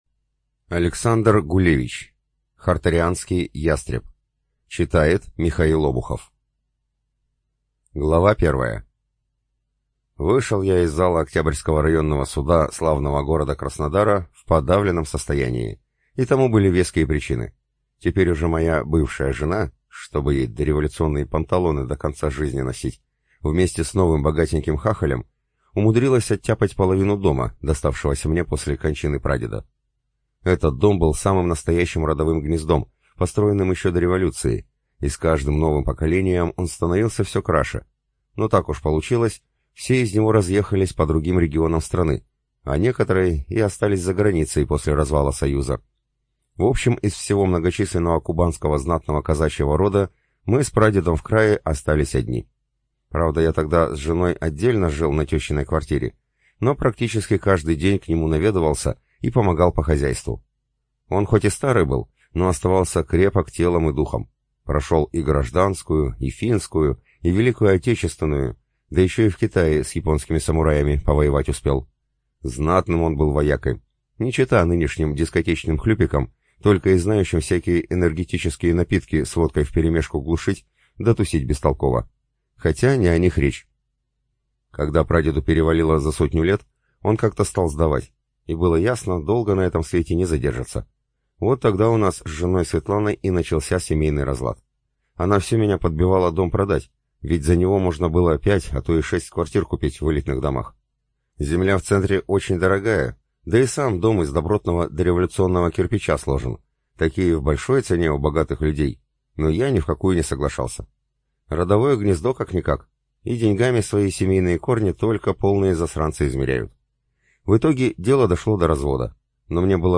ЖанрФантастика